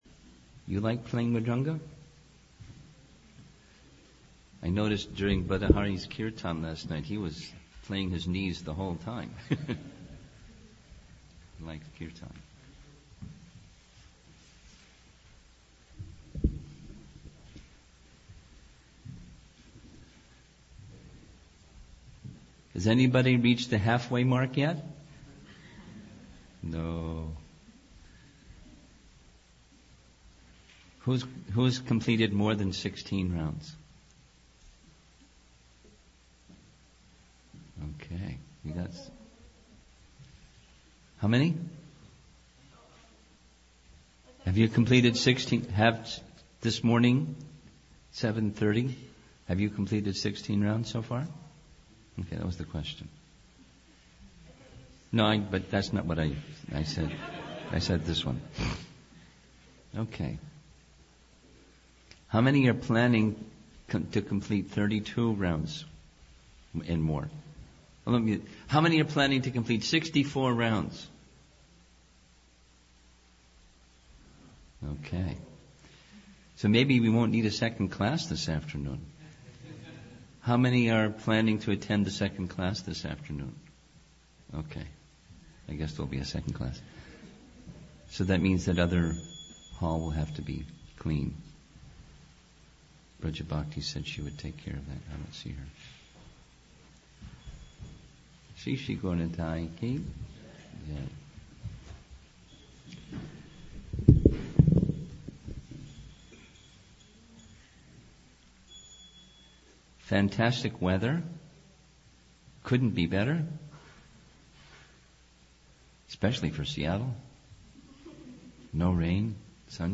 2015 Seattle Japa Retreat